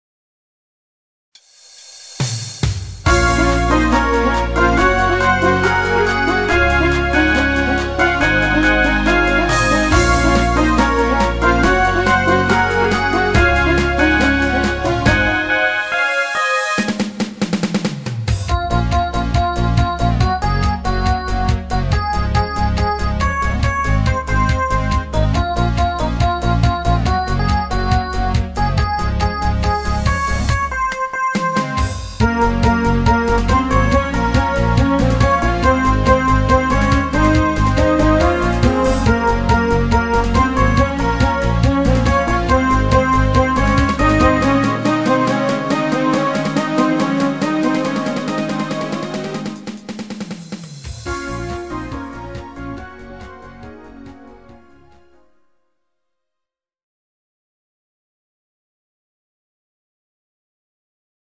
Genre(s): Deutschpop  Partyhits  |  Rhythmus-Style: Discofox
Fläche (Pad) als alternative Vocalistenspur